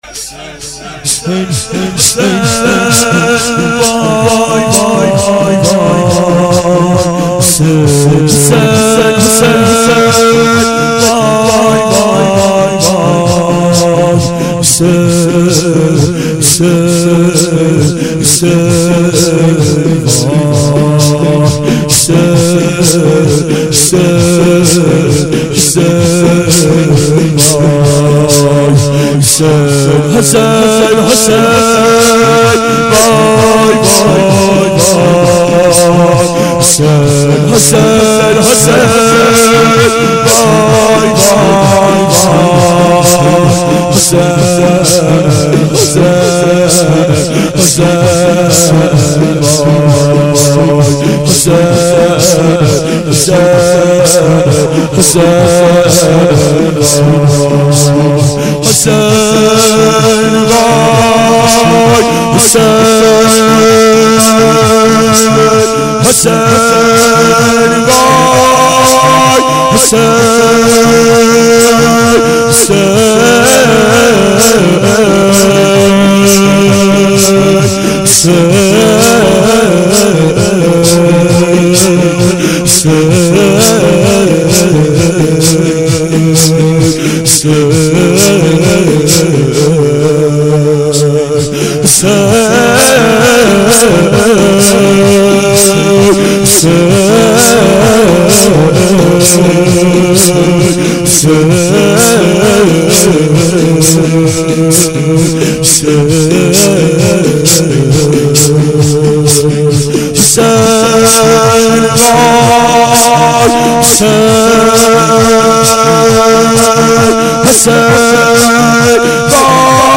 اربعین 90 هیئت متوسلین به امیرالمؤمنین حضرت علی علیه السلام